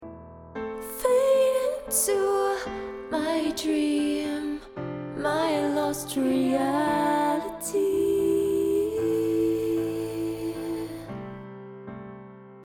Hier mal ein Beispiel: Lead Vox 0ms Predelay Back Vox 60ms Predelay Wer hat das Gefühl das die Back Vox weiter vorne stehen wegen mehr Predelay?